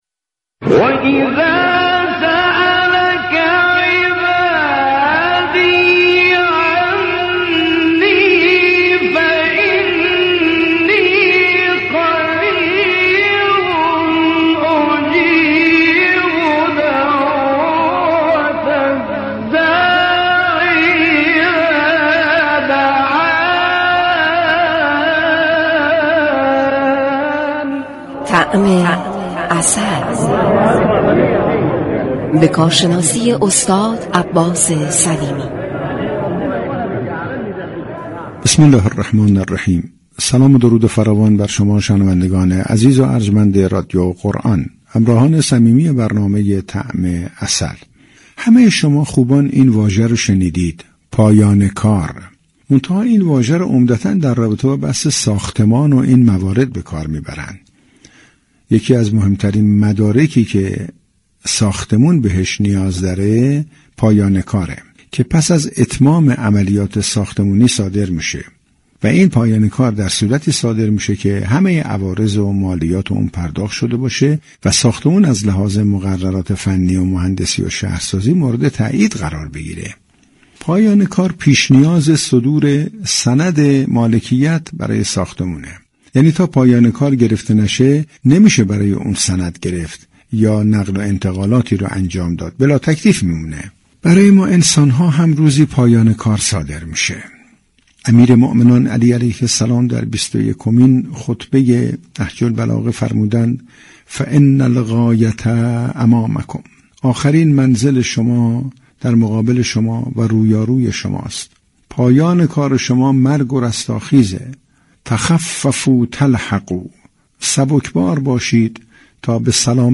گفتنی است؛ مجله صبحگاهی "تسنیم" با رویكرد اطلاع رسانی همراه با بخش هایی متنوع، شنبه تا پنجشنبه از شبكه ی رادیویی قرآن به صورت زنده تقدیم شنوندگان می شود.